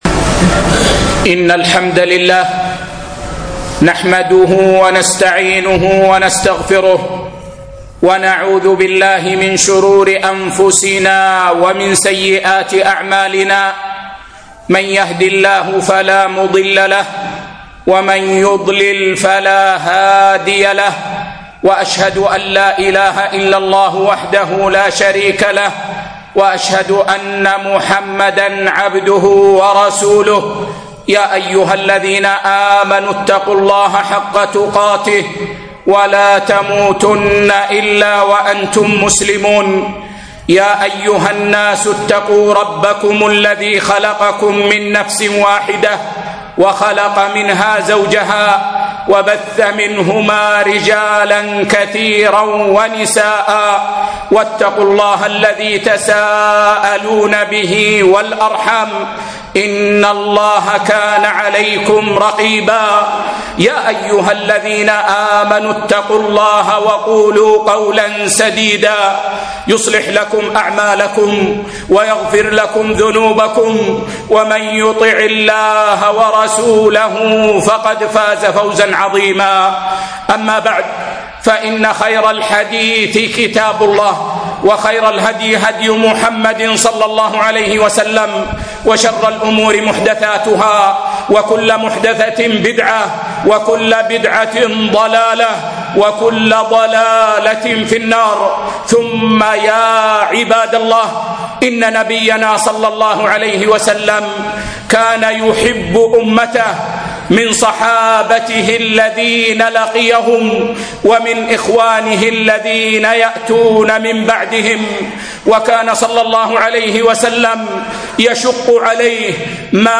الطهور شطر الإيمان - خطبة